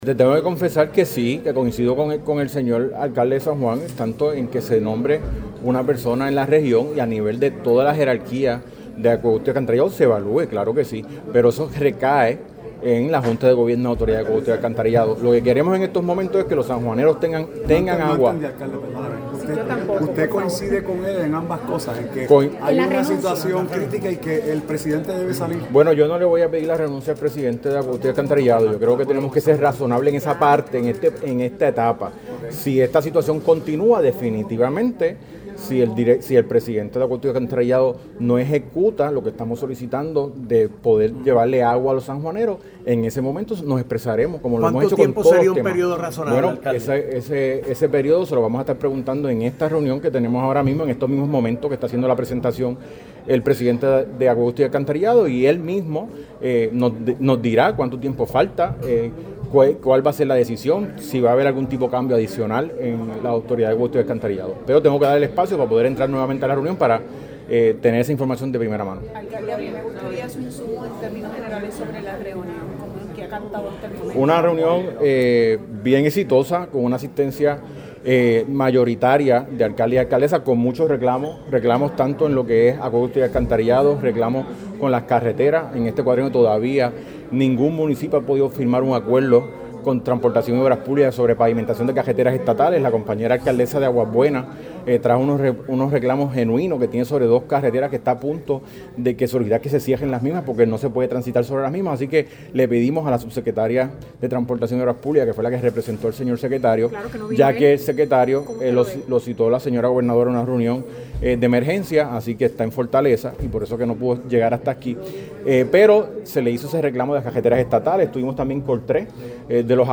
(24 de febrero de 2026)-Tras la reunión que llevó a cabo la Federación de alcaldes, el presidente Gabriel “Gaby” Hernández y alcalde de Camuy, a preguntas de la prensa sobre la situación del problema del servicio de agua potable en el municipio de San Juan, Miguel Romero, manifestó que si coincide con la preocupación del alcalde ante los reclamos que hace al presidente de la AAA Luis González.